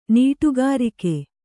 ♪ nīṭugārike